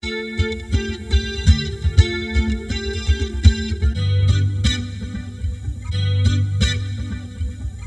122 Bpm 韵律吉他
描述：韵律吉他，Fender Tele。和弦。Dm G / G A7
Tag: 122 bpm Pop Loops Guitar Electric Loops 1.32 MB wav Key : D